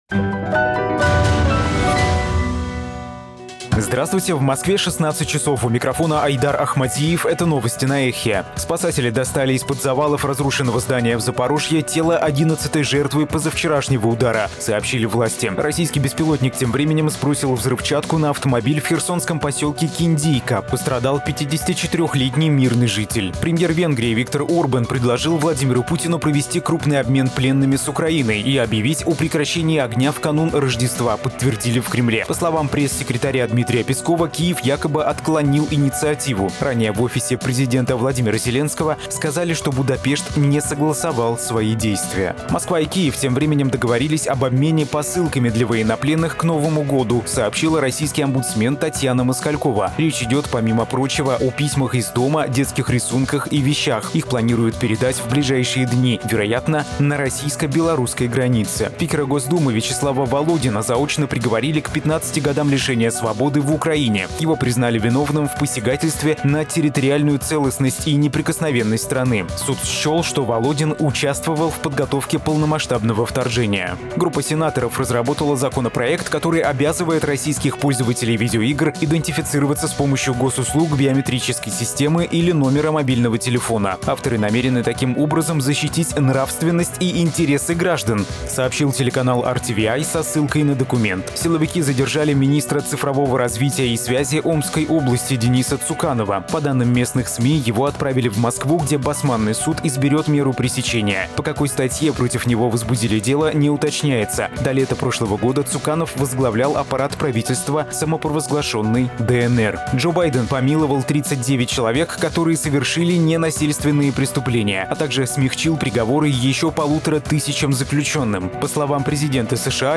Новости - ЭХО
Слушайте свежий выпуск новостей «Эха»